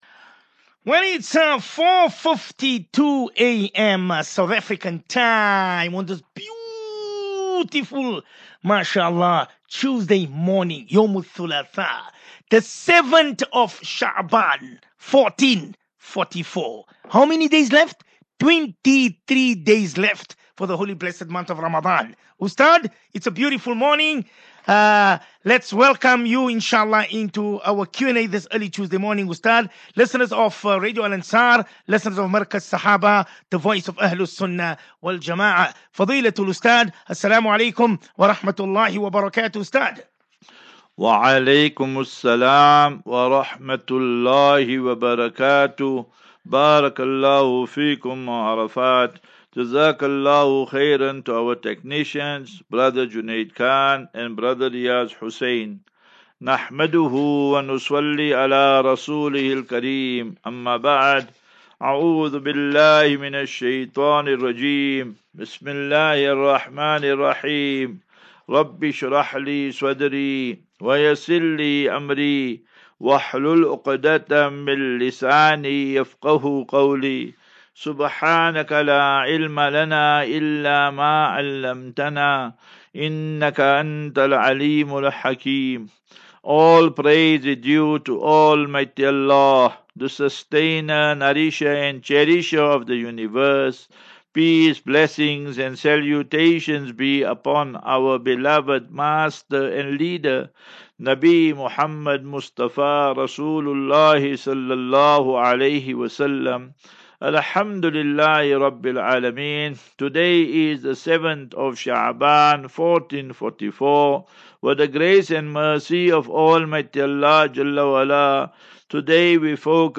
View Promo Continue Install As Safinatu Ilal Jannah Naseeha and Q and A 28 Feb 28 Feb 23 Assafina tu Illal Jannah 36 MIN Download